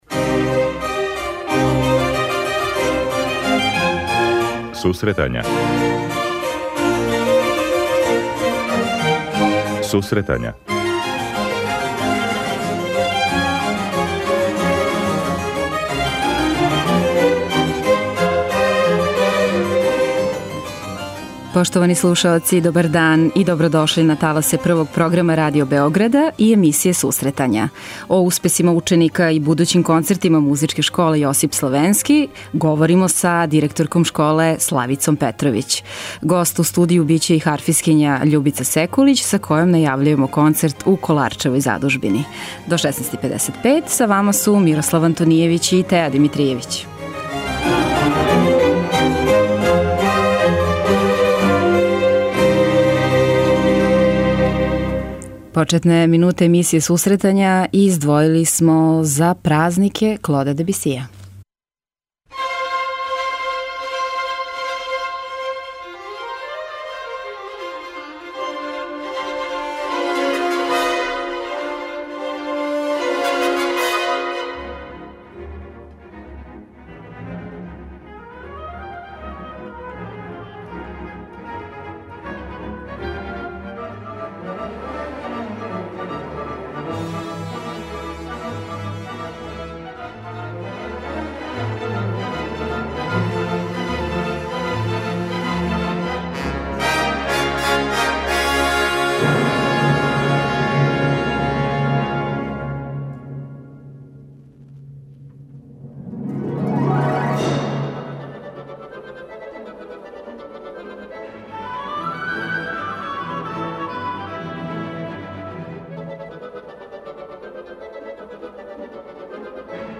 преузми : 52.28 MB Сусретања Autor: Музичка редакција Емисија за оне који воле уметничку музику.